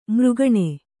♪ mřgaṇe